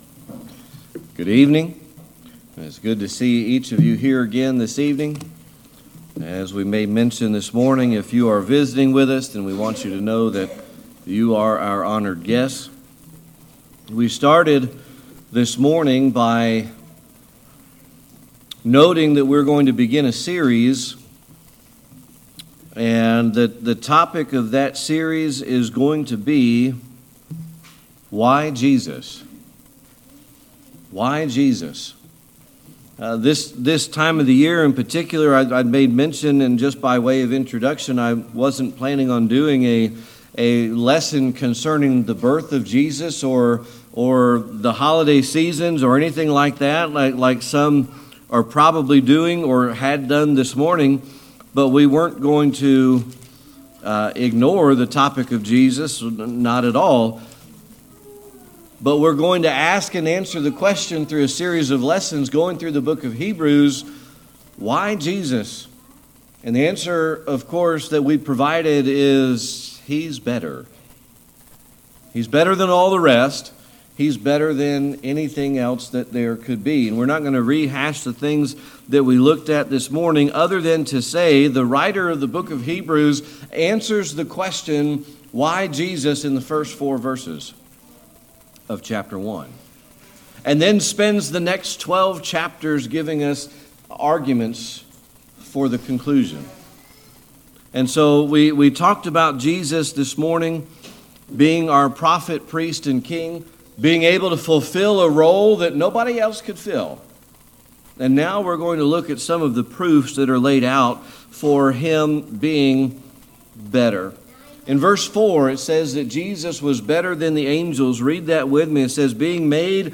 Service Type: Sunday Evening Worship